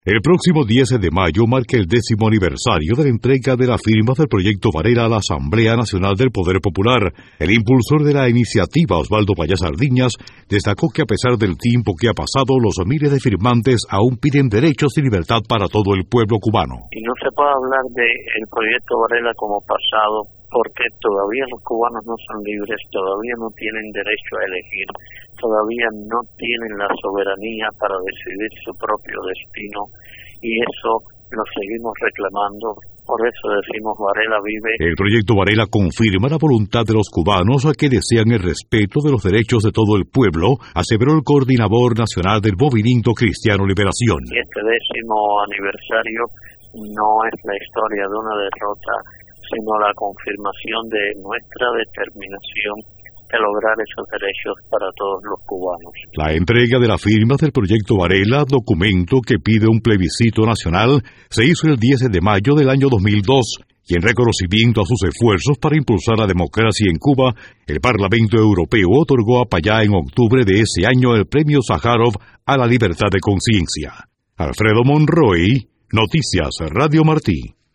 Un informe